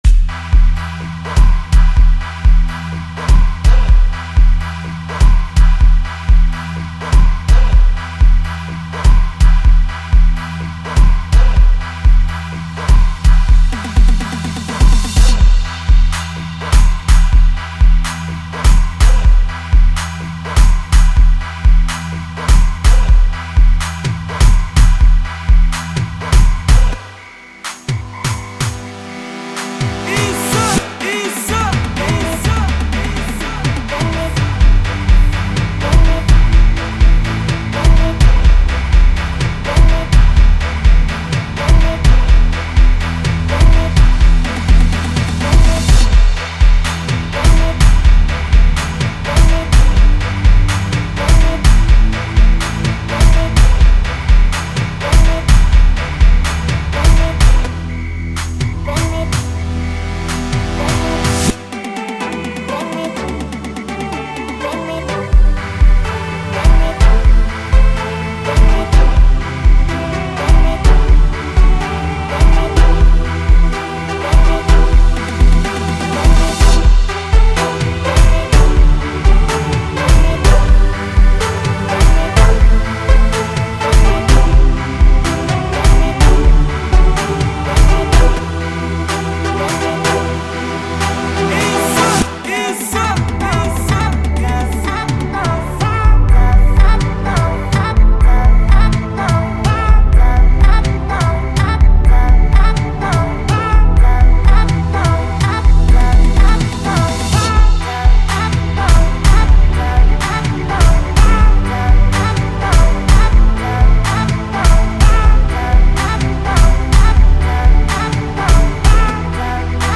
heartwarming song